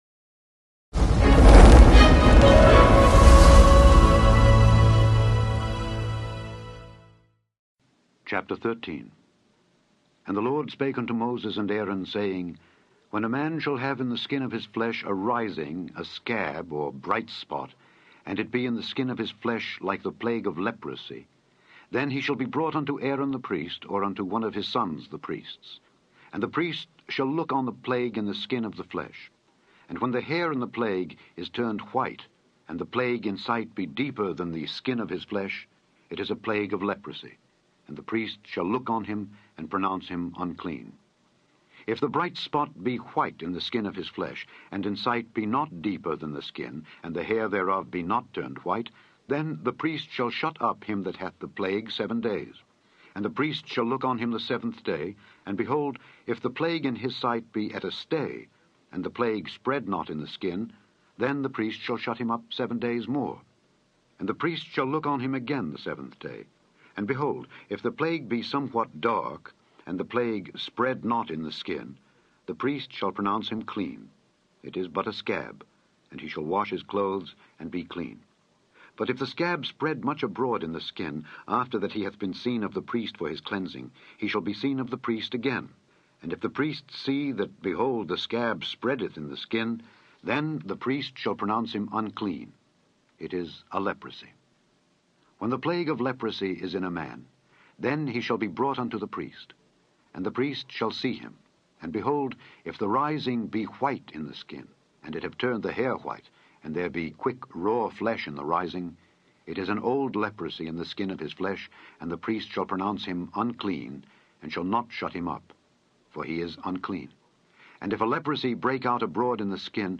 In this podcast, you can listen to Alexander Scourby read Leviticus 13-15 to you.